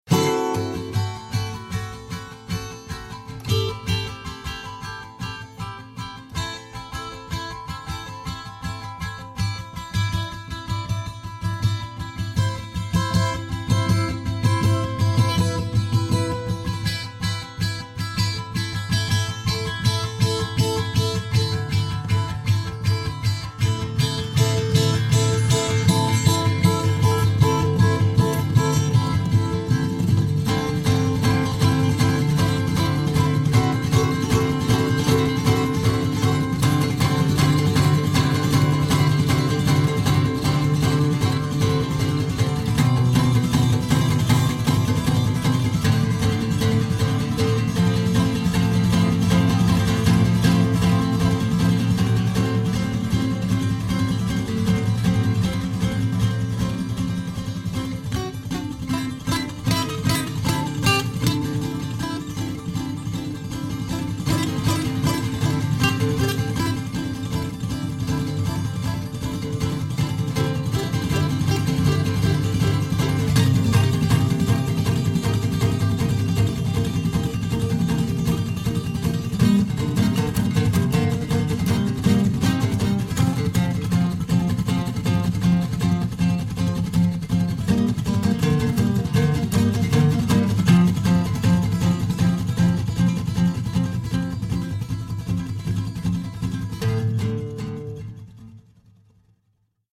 Akustische Gitarren